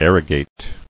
[ar·ro·gate]